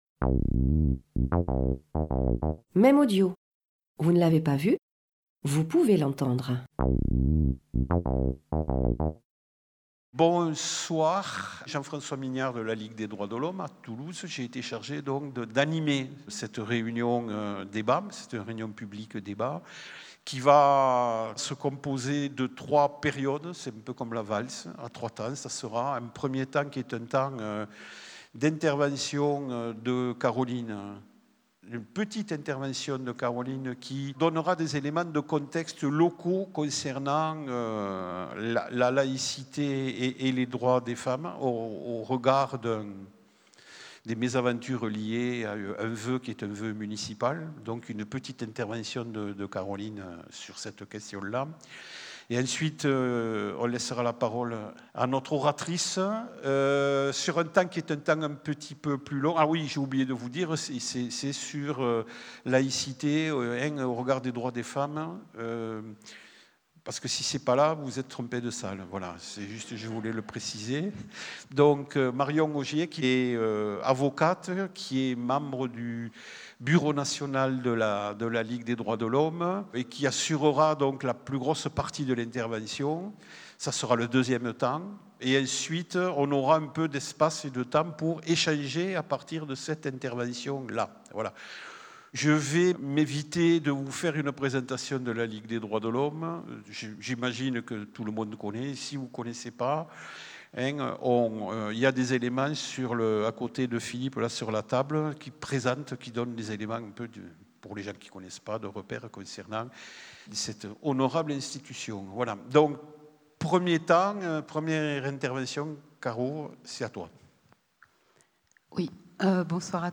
Conférence - débat
Salle du Sénéchal, Toulouse, le 28 février 2026